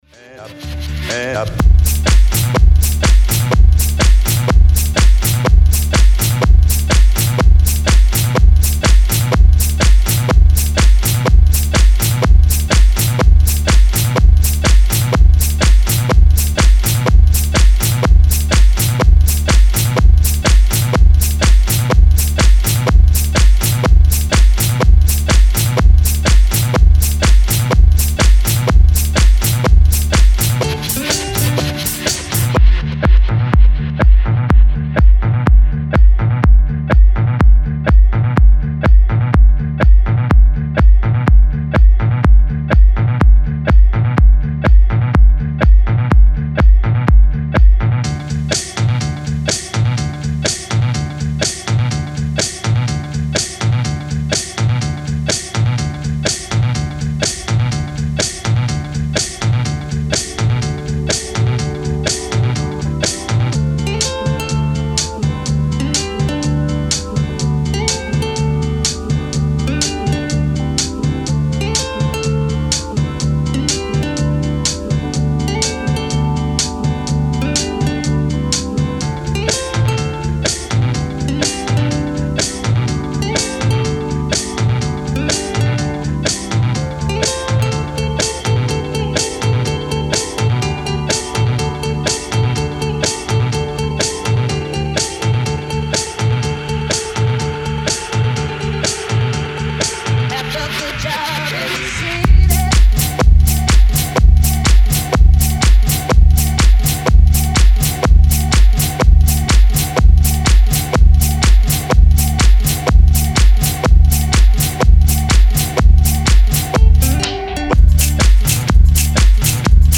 Style: House / Tech House
A1 Original Mix